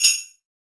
WJINGLE BE2R.wav